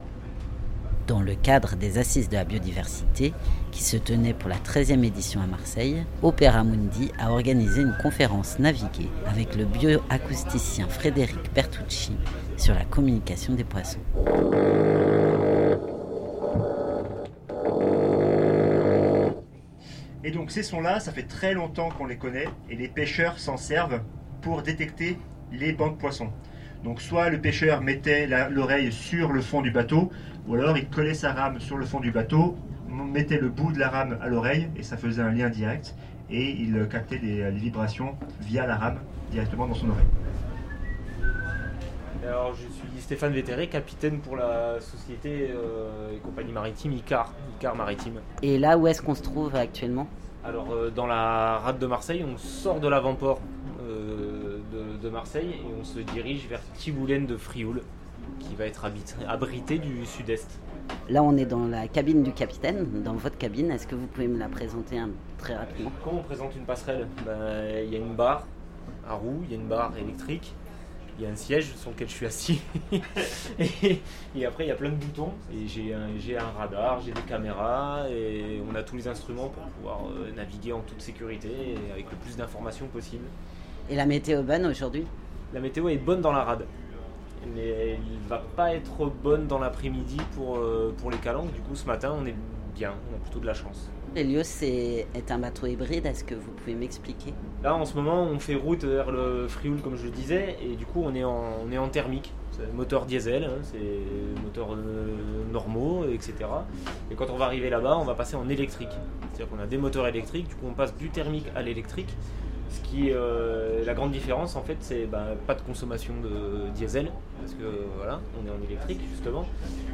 Conférence naviguée